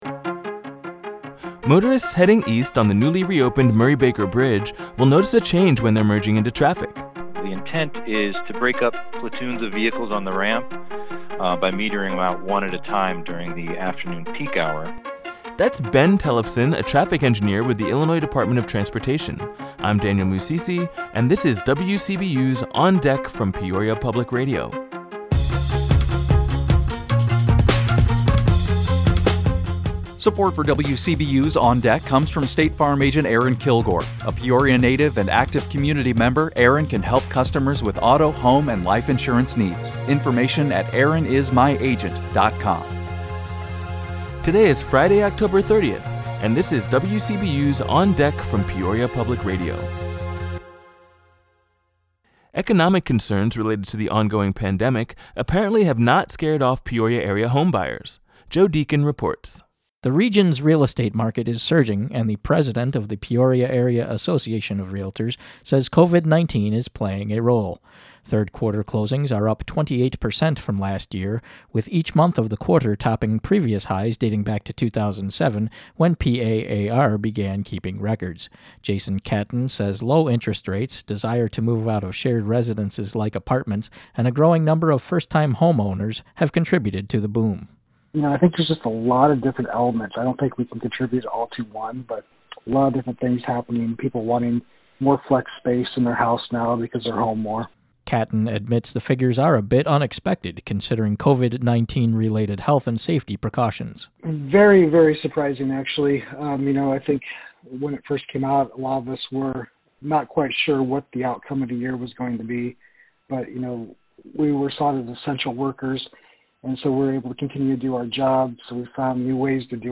Illinois News